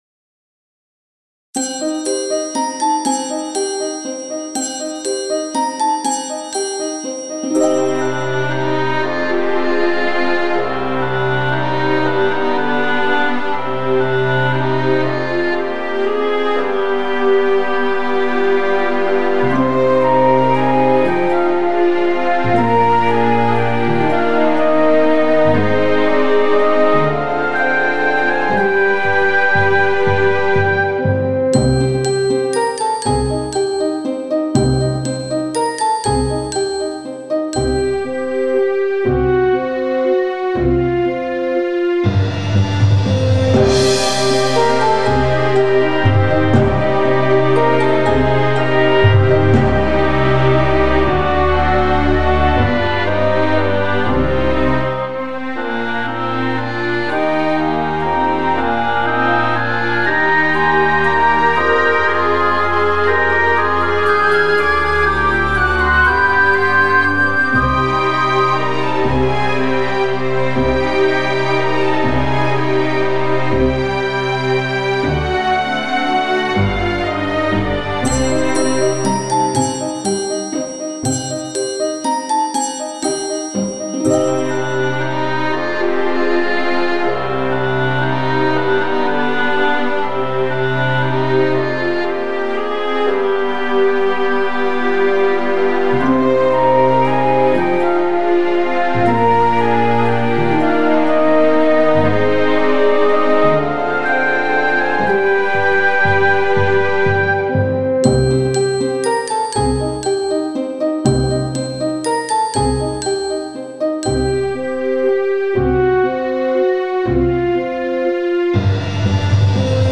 Pastorałka kaszubska
Podkład muzyczny tytuł